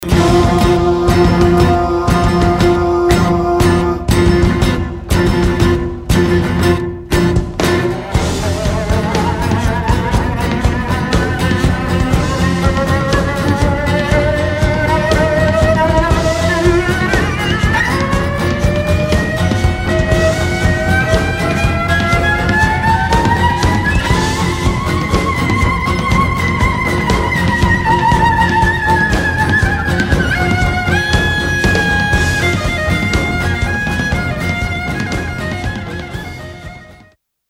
Miking the Cello in the Studio
1. Bridge
• Tone: Bright, full, and “in-your-face.”
• Distance: 12- 15 inches from the bridge.